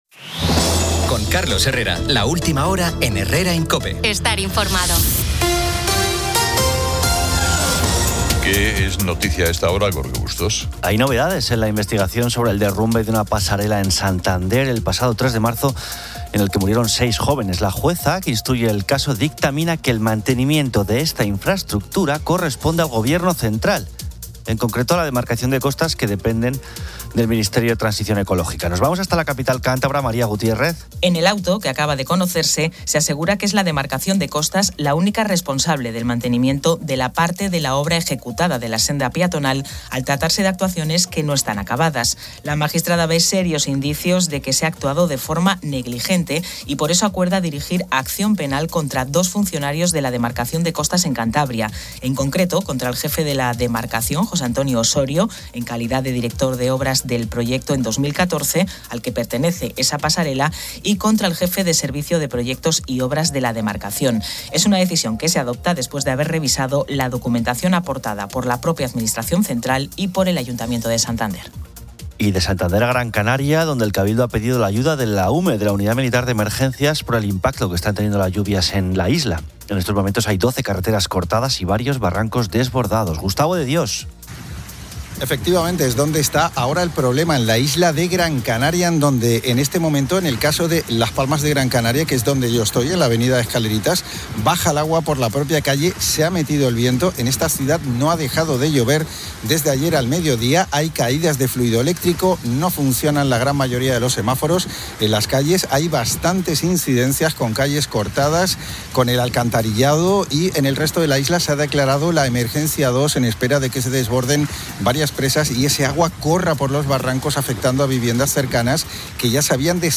El programa también ofrece una entrevista con Cayetano Martínez de Irujo, quien habla del centenario de su madre, la Duquesa de Alba, destacando su legado patrimonial y su singular vida.